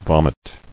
[vom·it]